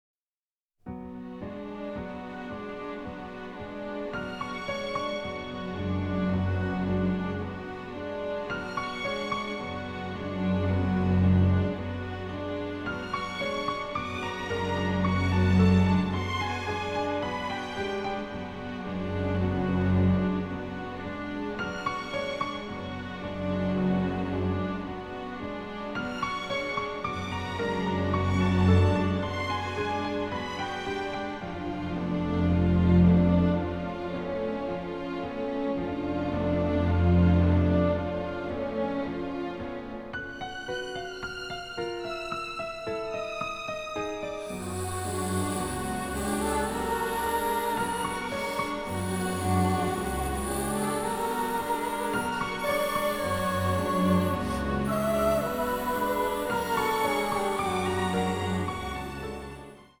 suspense score